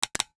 NOTIFICATION_Click_10_mono.wav